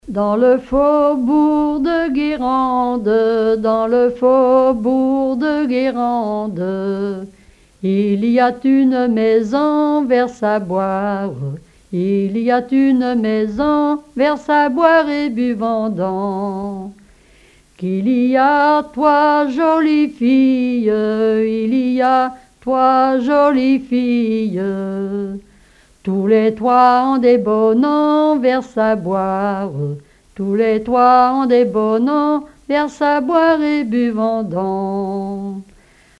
Genre laisse
Témoignages et chansons traditionnelles
Pièce musicale inédite